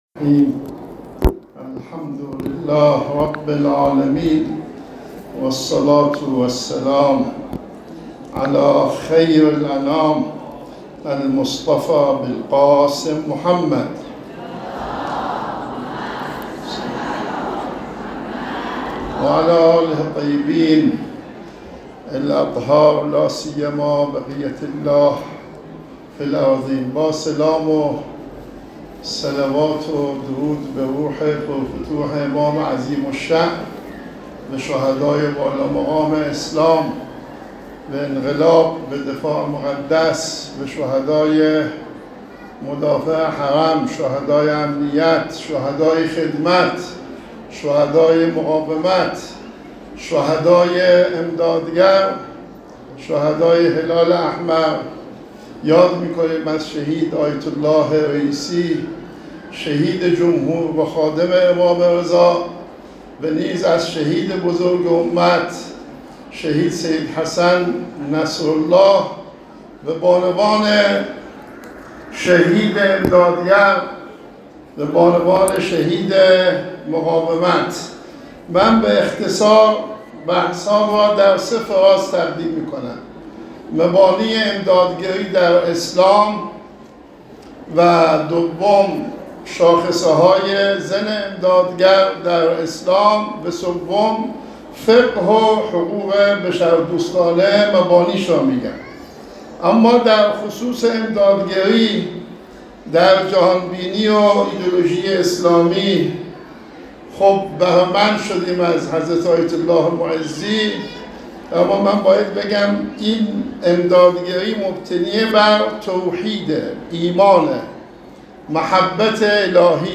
بیانات ایت الله کعبی در همایش بین المللی مبلغان بشر دوستی